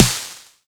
SNARE18.wav